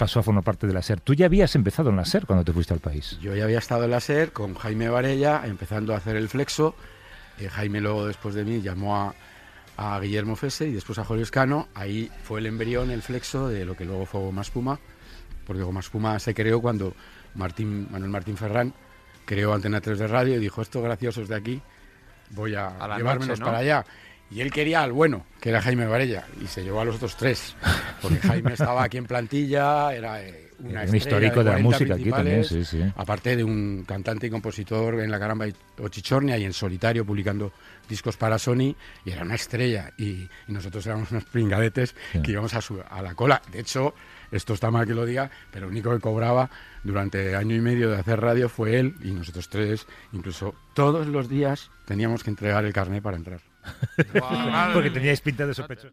Entrevista a Santiago Alcanda sobre els seus inicis a la Cadena SER
Entreteniment